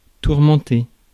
Ääntäminen
France: IPA: /tuʁ.mɑ̃.te/